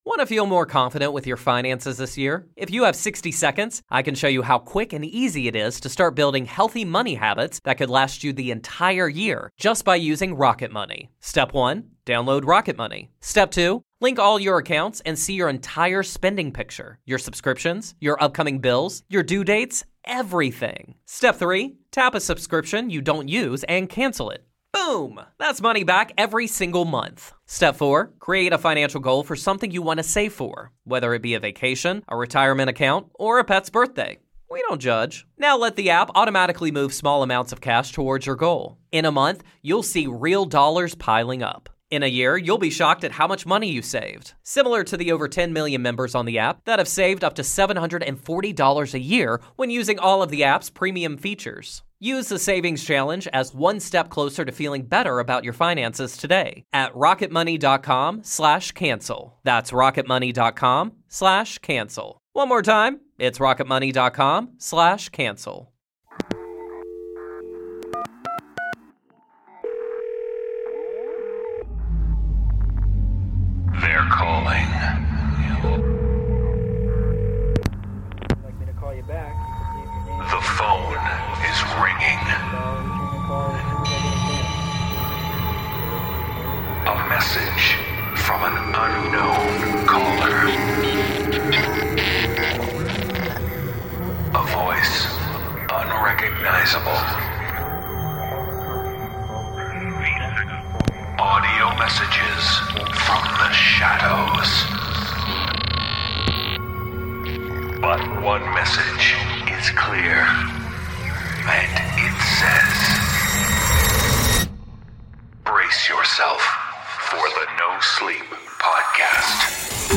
The voices are calling with tales of unhelpful helpers.